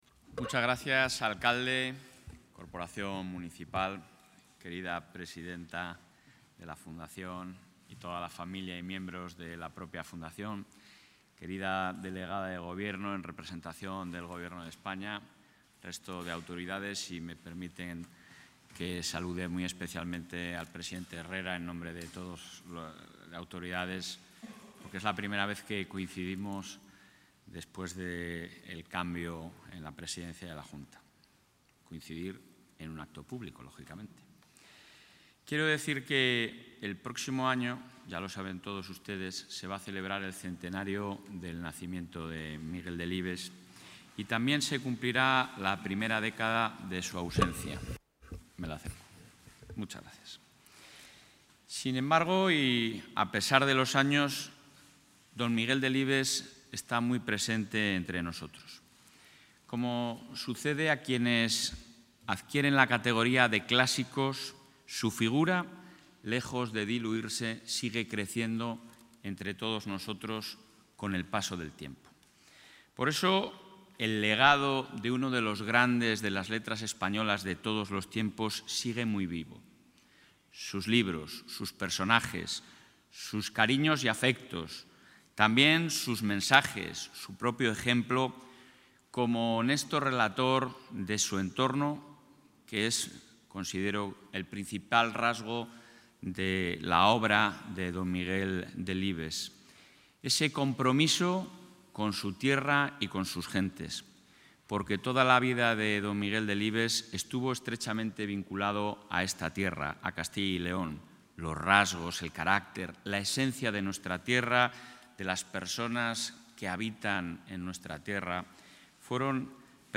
Intervención presidente.
Durante la presentación de la programación en homenaje a Delibes, el presidente del Ejecutivo autonómico, Alfonso Fernández Mañueco, ha asegurado que el universal escritor es una figura esencial en el conocimiento y en la identidad de Castilla y León